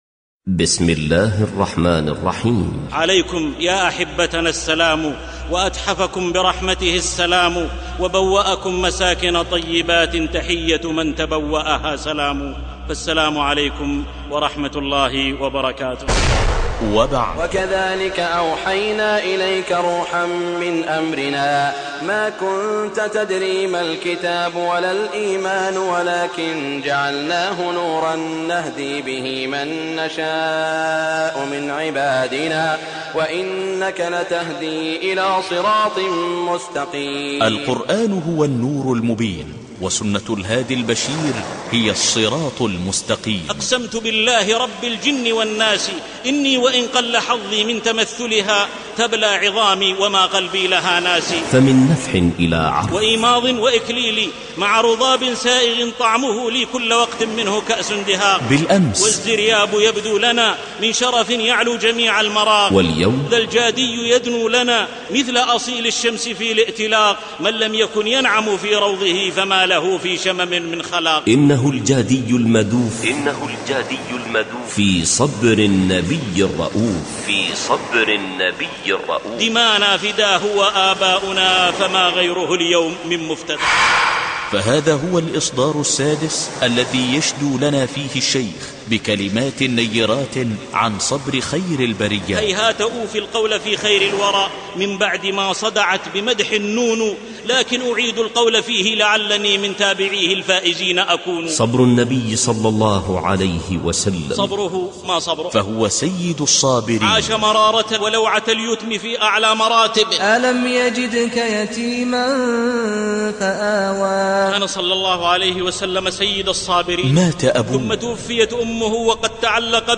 محاضرة سيد الصابرين